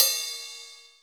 RIDE CS1  -S.WAV